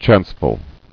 [chance·ful]